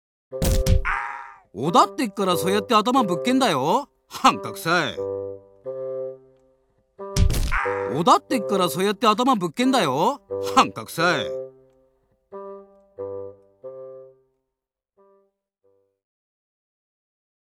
北海道弁を読み上げる“新感覚かるた”！
BGM・効果音付きで楽しさ倍増